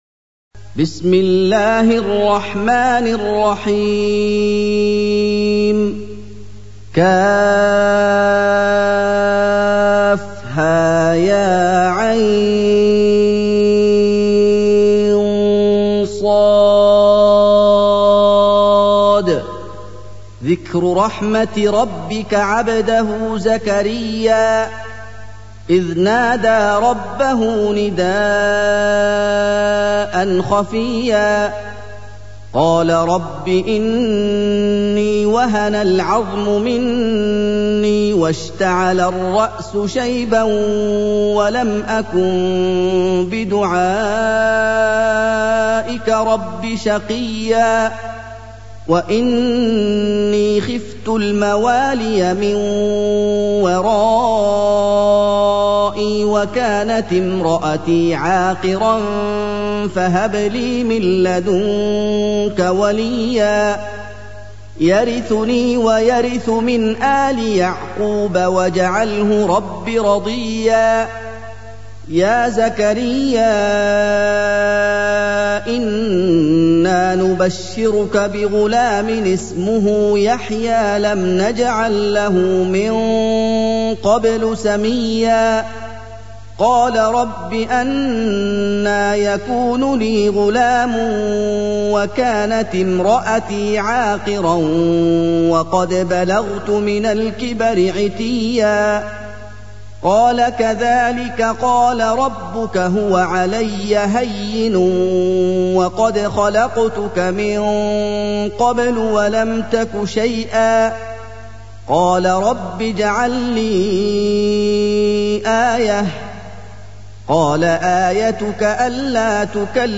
سُورَةُ مَرۡيَمَ بصوت الشيخ محمد ايوب